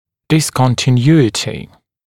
[ˌdɪsˌkɔntɪ’njuːətɪ][дисˌконти’нйу:эти]прерывность, прерывистость, разрыв